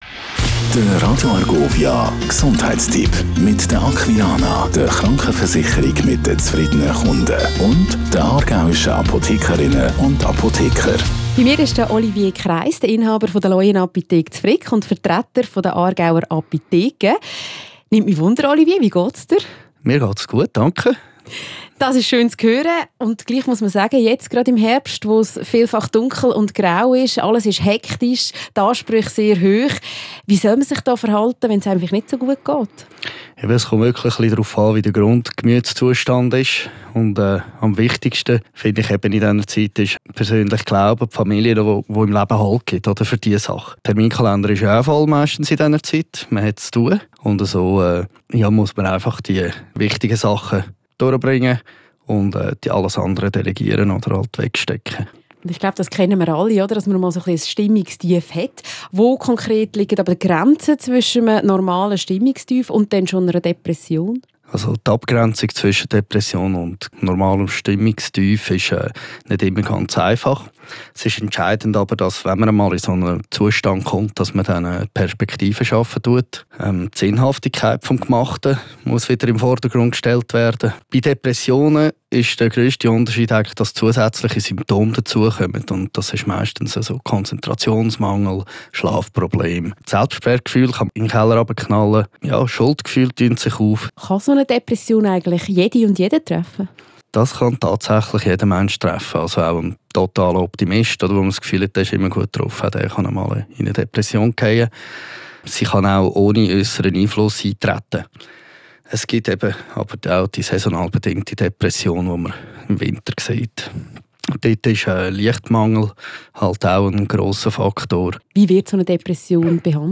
Der Aargauische Apothekerverband gibt regelmässig Gesundheitstipps in Form von kurzen Radiobeiträgen heraus.